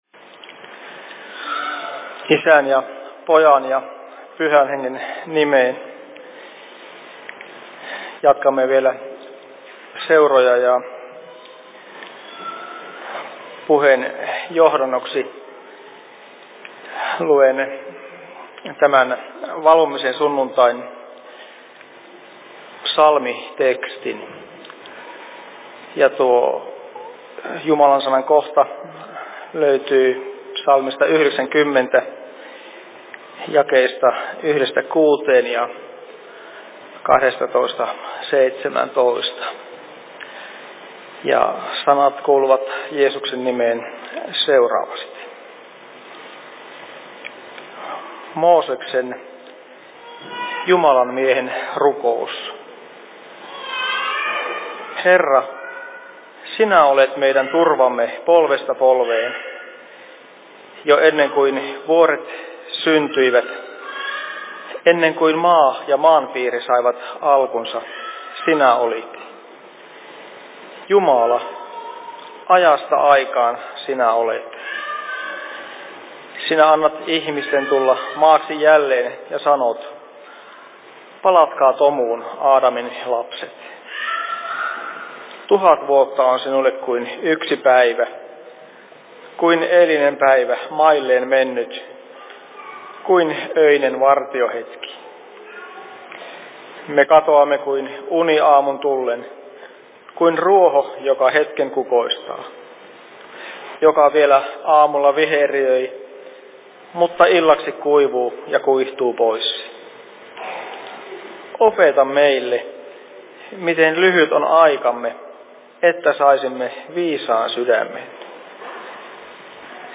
Seurapuhe Kempeleen RY:llä 14.11.2021 17.25
Paikka: Rauhanyhdistys Kempele